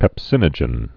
(pĕp-sĭnə-jən)